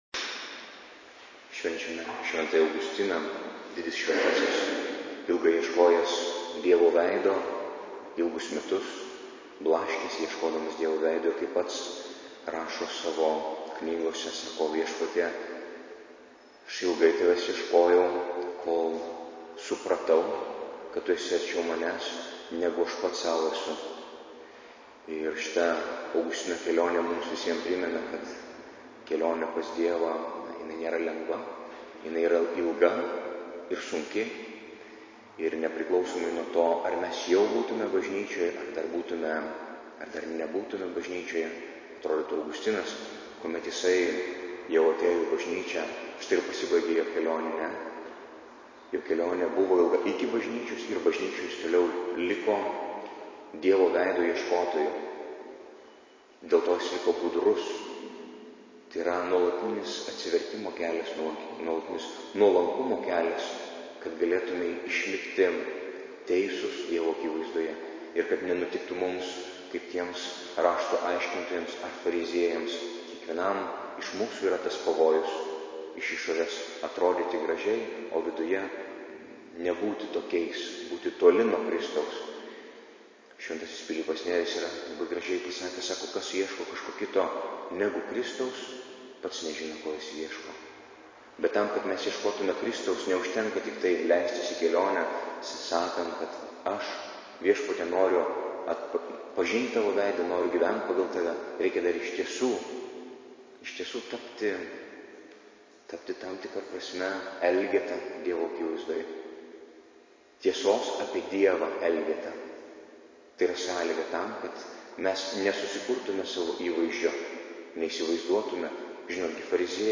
Audio pamolslas: